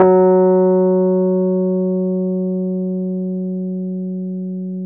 RHODES-F#2.wav